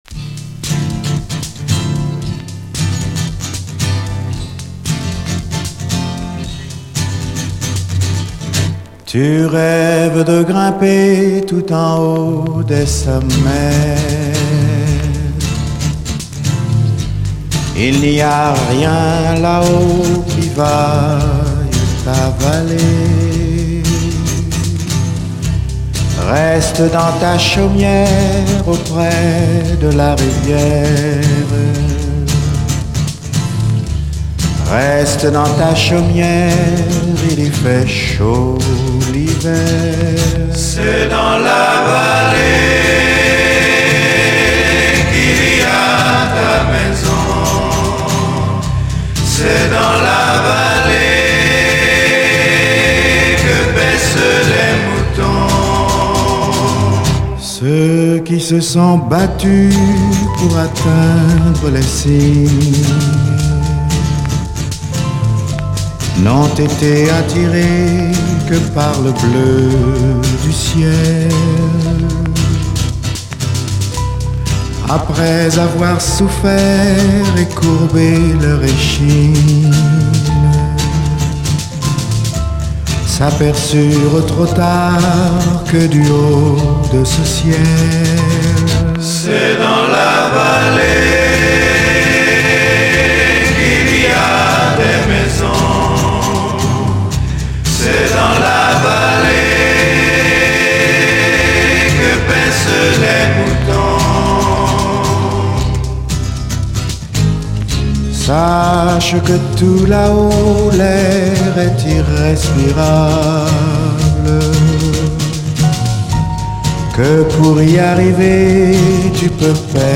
SOUL, 70's～ SOUL, SSW / AOR, 7INCH
知られざる最高の哀愁アコースティック・フレンチ・メロウ・ソウル！
全て彼自身の手で録音されたエレガントな楽曲、美しいコーラス